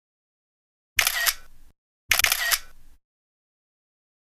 Camera Shutter (selfie)